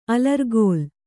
♪ alargōl